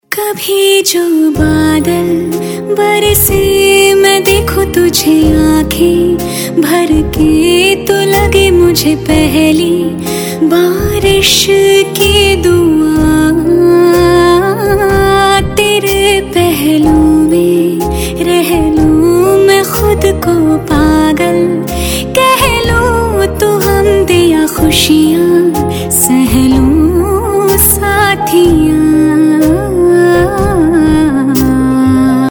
.mp3 Song Download Bollywood Mazafree